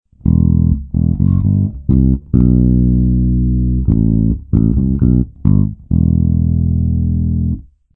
Bajo eléctrico tocado con dedos
Este enfoque da un sonido redondo, estable y muy musical.
bajo-tocado-con-dedos-1.mp3